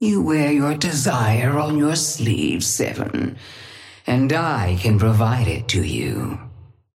Sapphire Flame voice line - You wear your desire on your sleeve, Seven. And I can provide it to you.
Patron_female_ally_gigawatt_start_03.mp3